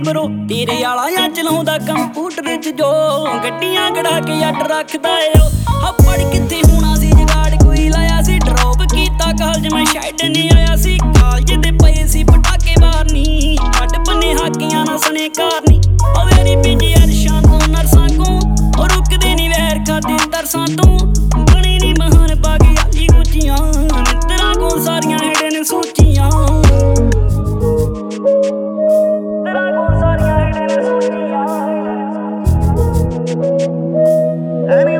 Жанр: Инди / Местная инди-музыка